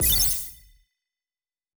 super_bullet.wav